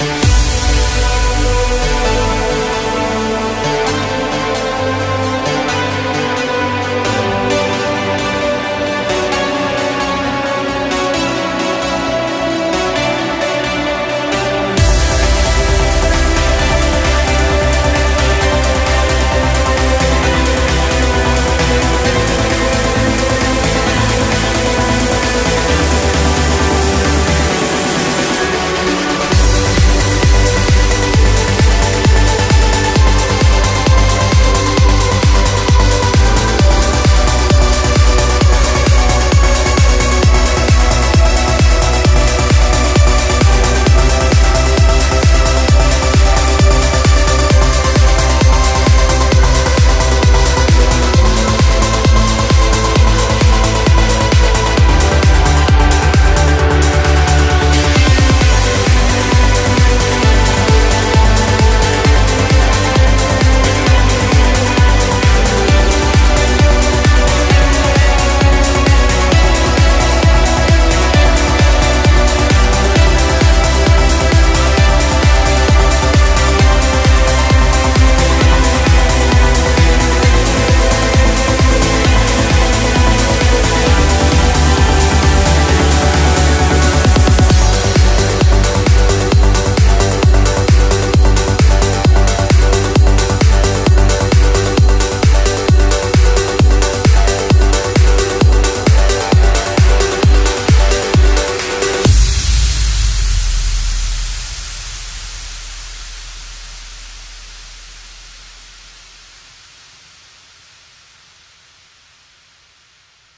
BPM132
Audio QualityPerfect (High Quality)
Comments[NEW MILLENNIUM TRANCE]